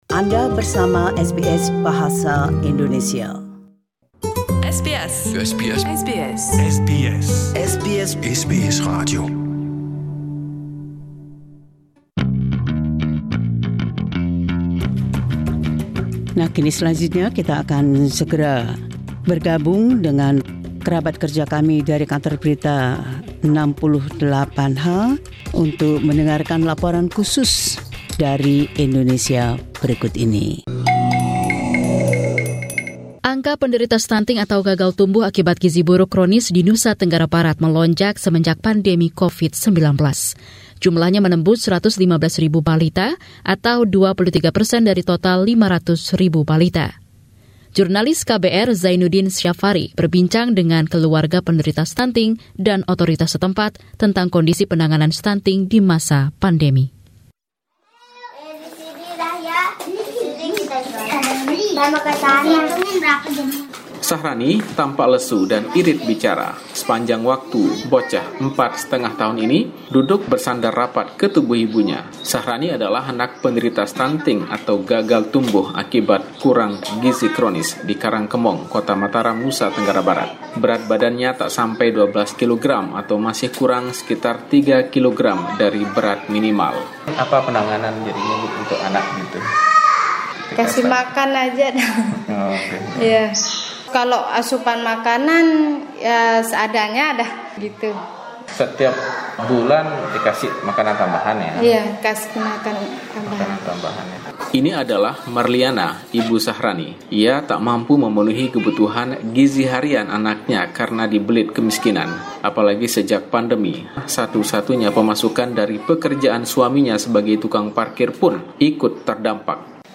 The team at KBR 68H report on a worrying trend.